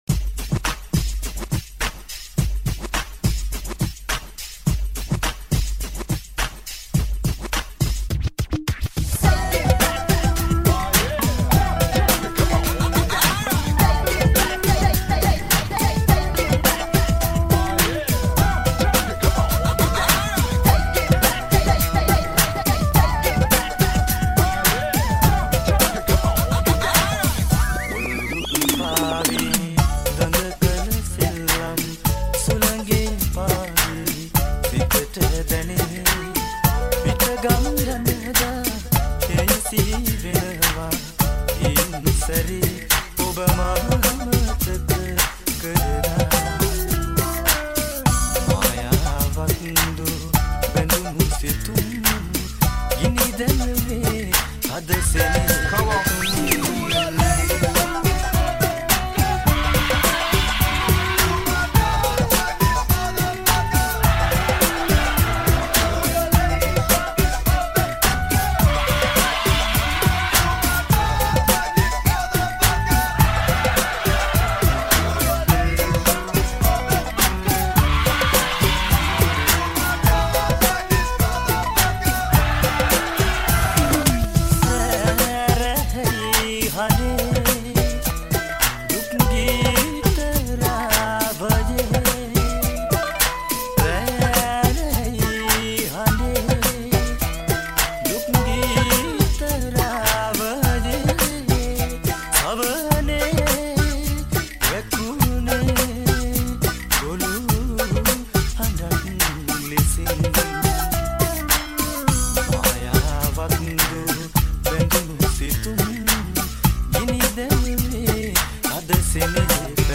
Sinhala Remix New Song